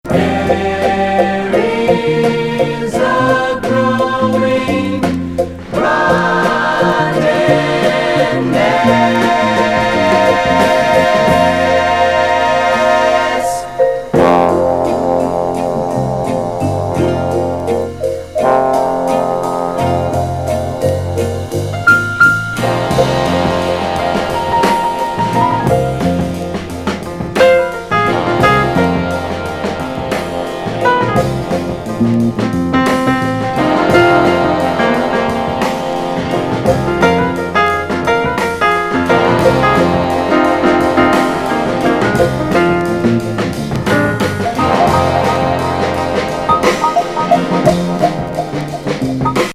ミュージカル仕立てのサイケ・
フォーキー・ファンク・グルーヴ!